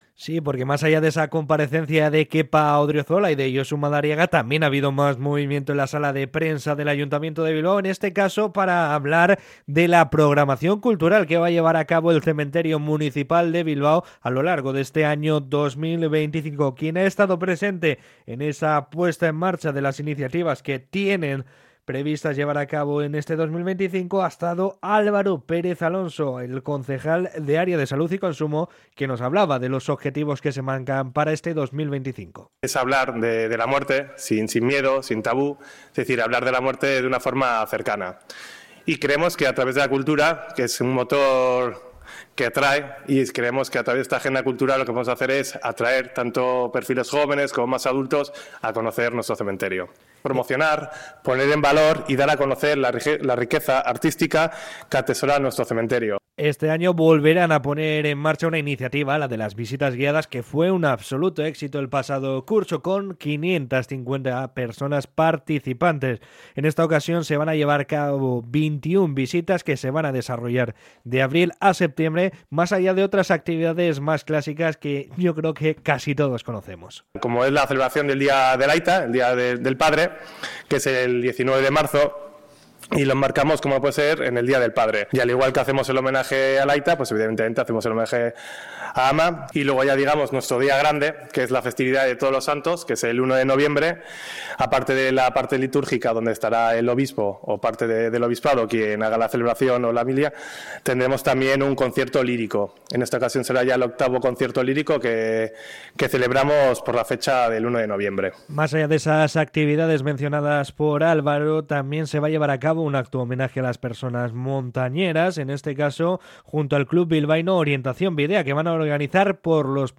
13.-CRONICA-CEMENTERIO.mp3